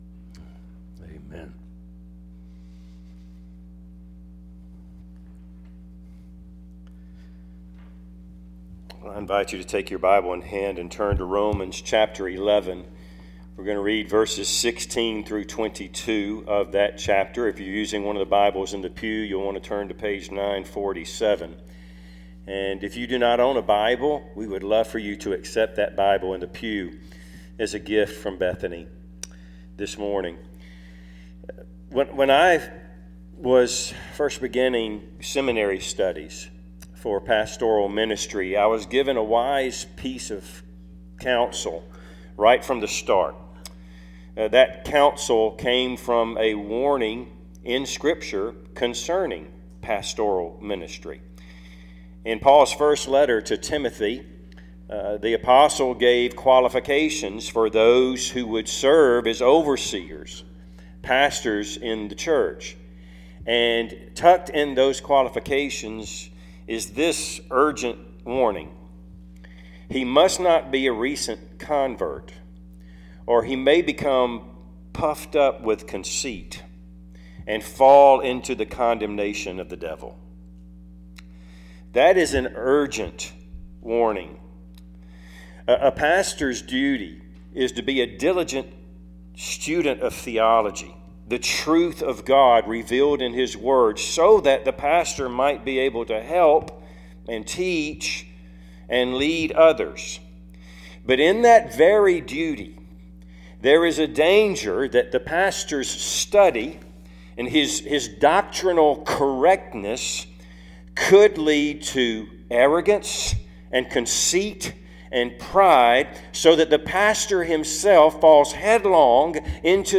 Service Type: Sunday AM Topics: Faith , Grace , pride , Salvation